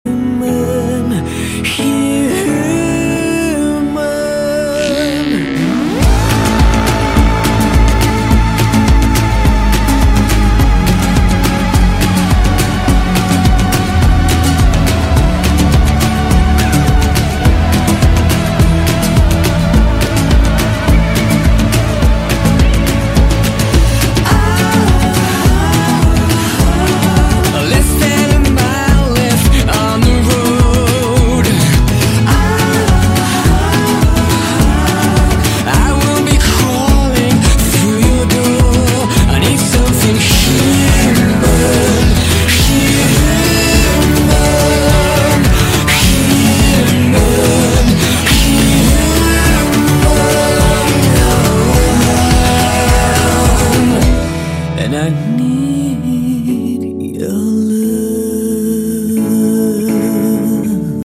Ringtones Category: Pop